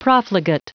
Prononciation du mot profligate en anglais (fichier audio)
Prononciation du mot : profligate